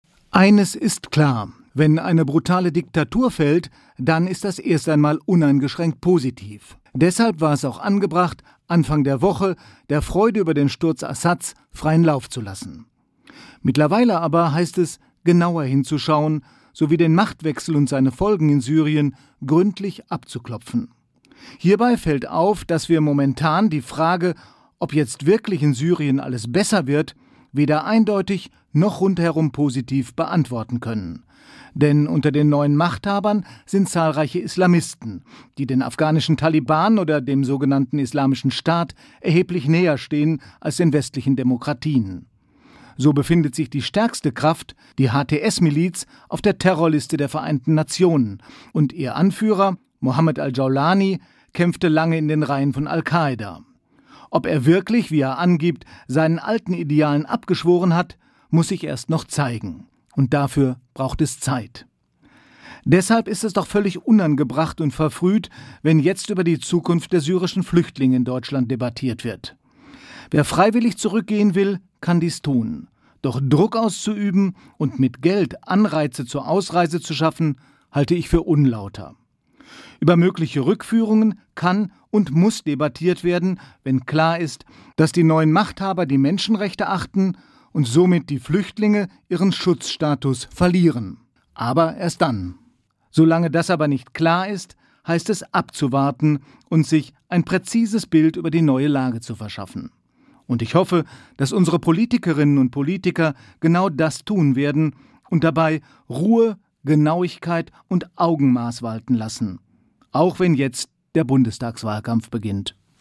Der Standpunkt in SWR1 Sonntagmorgen